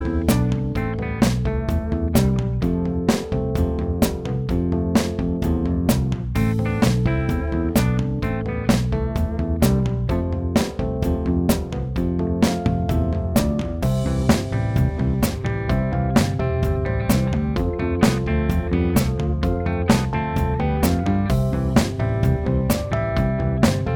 Minus Solo Guitar Pop (1980s) 4:06 Buy £1.50